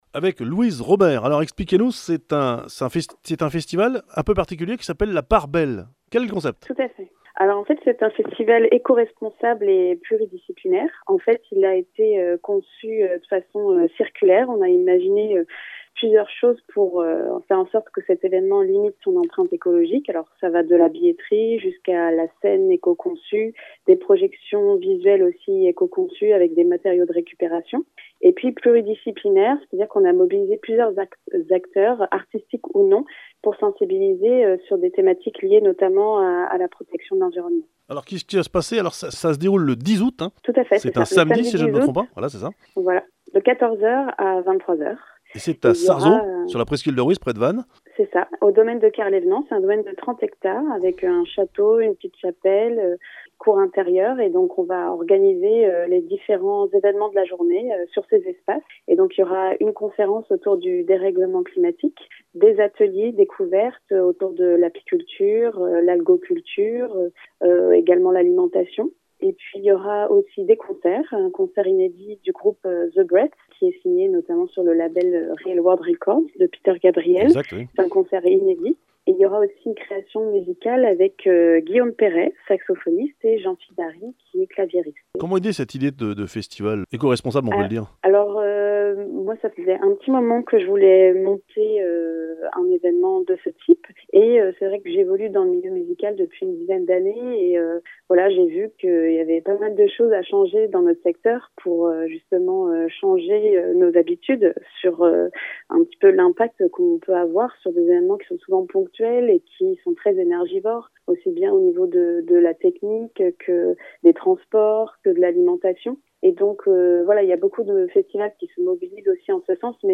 (Interview Radio Korrigans, Radio Balises, Radio Larg, Radio Sud Belgique…)